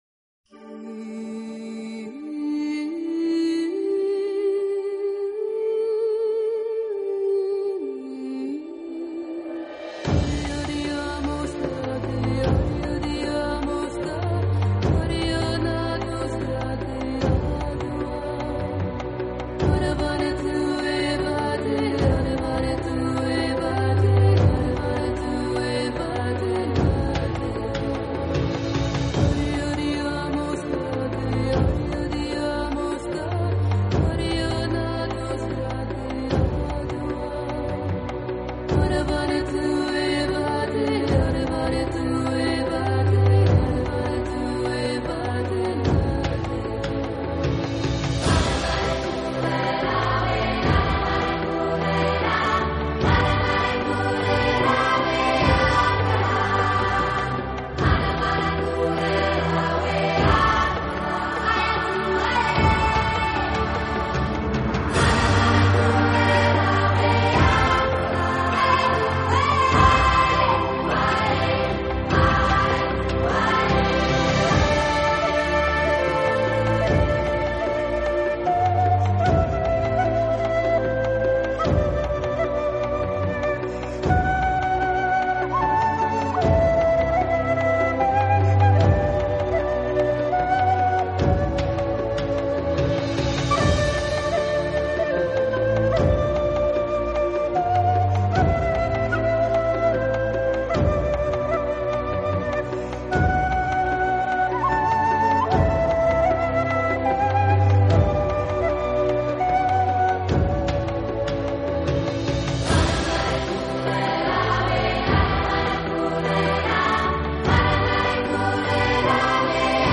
音乐类型：New Age、Contemporary Instrumental、Electronica
专辑语言：轻音乐
都是圣歌与现代器乐的融合，甚至乍听起来，还颇为相似。
是领唱上，都加重了女声的成分，因而整体风格都显得更柔和、更温暖。
强，很易上口，感觉上更流行化一些吧。